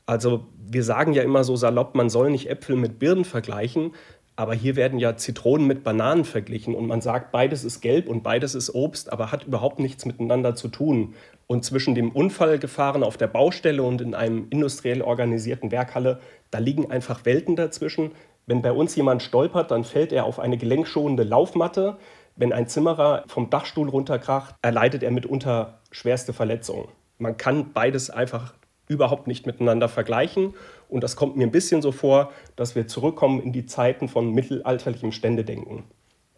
O-Ton: Willkür macht Hausbau teurer